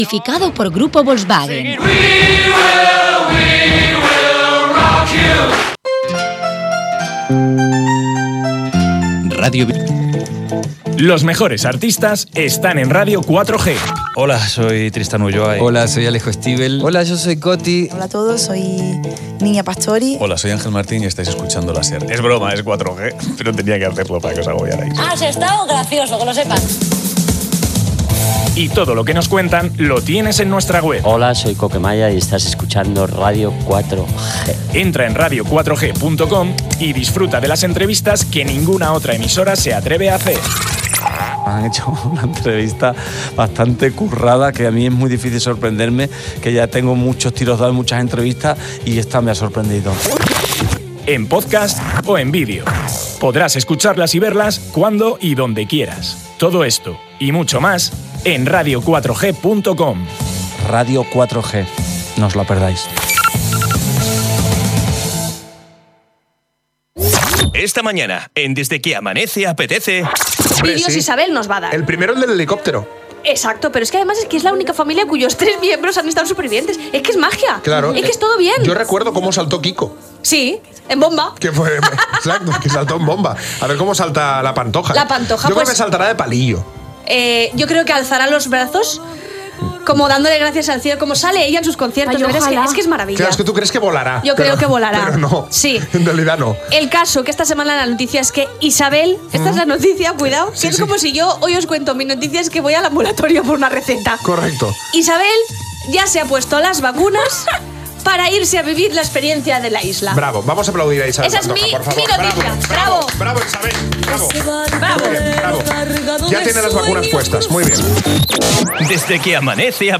La tertúlia de los lunes en Ràdio Vila-real.